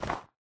MinecraftConsoles / Minecraft.Client / Windows64Media / Sound / Minecraft / step / snow4.ogg
snow4.ogg